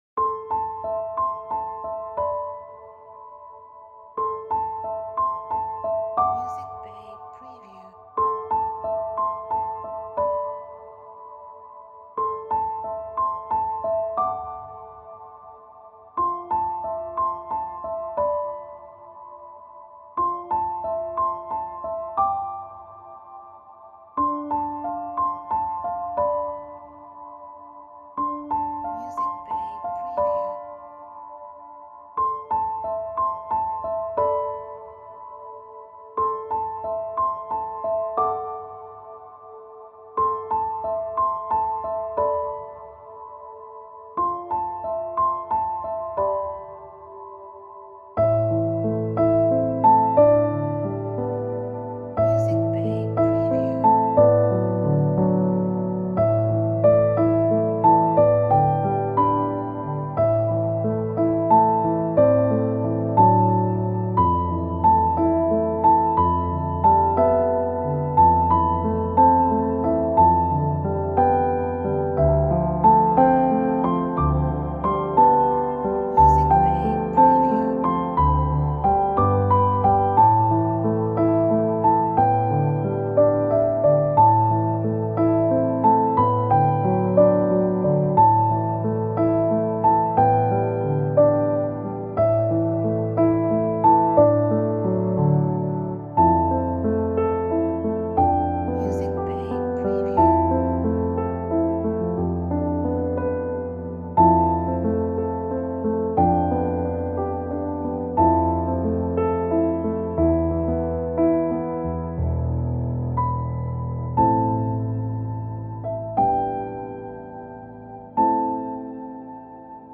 Emotional background music. Romantic royalty free music.